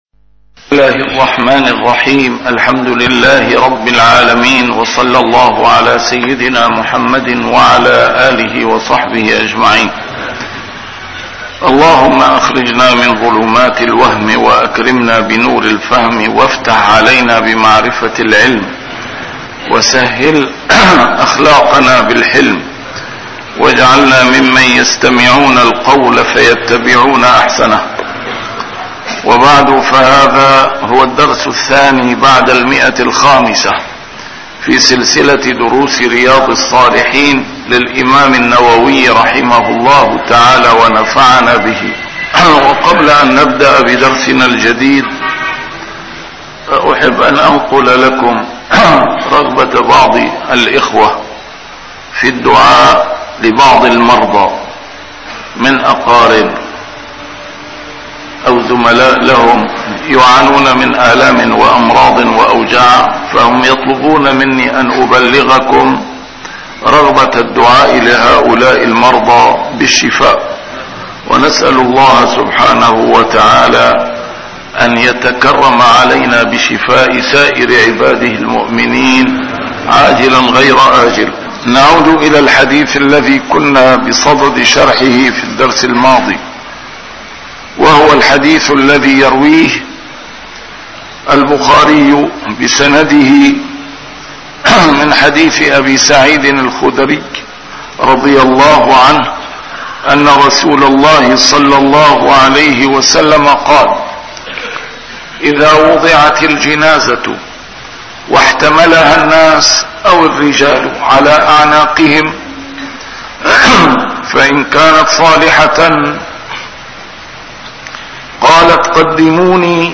A MARTYR SCHOLAR: IMAM MUHAMMAD SAEED RAMADAN AL-BOUTI - الدروس العلمية - شرح كتاب رياض الصالحين - 502- شرح رياض الصالحين: الجمع بين الخوف والرجاء